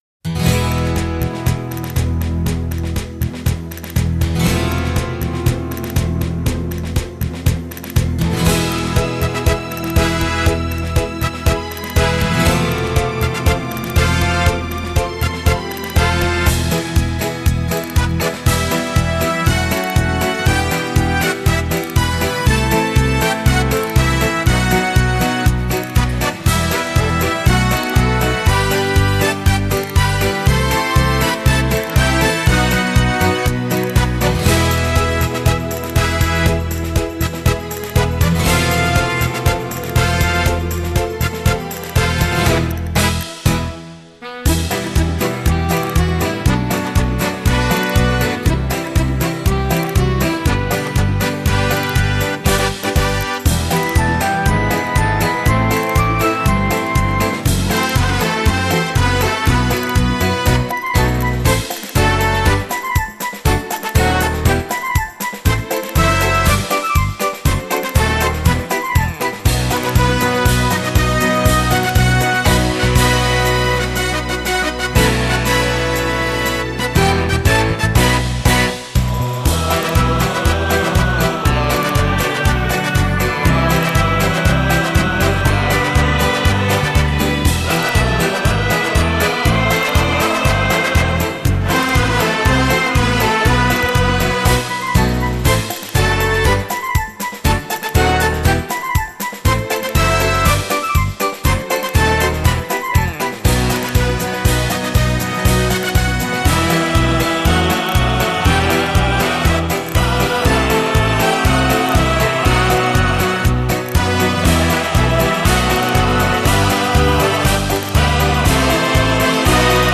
01 Paso Double